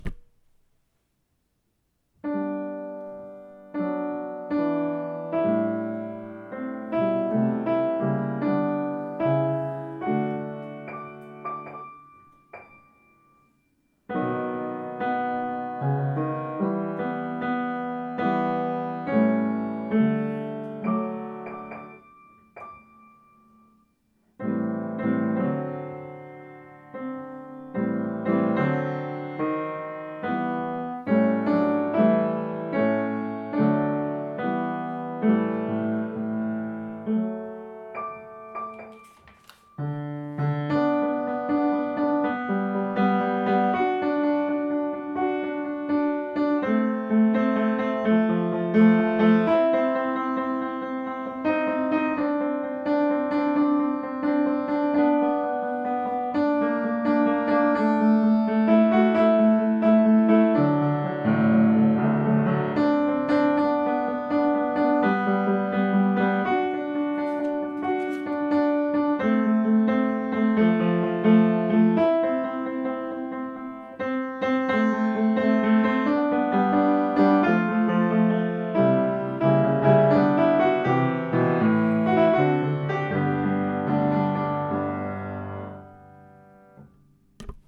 Oefenbestanden Cavalleria Rusticana Regina Coeli
Regina Tenor 1
Regina1Tenor1.mp3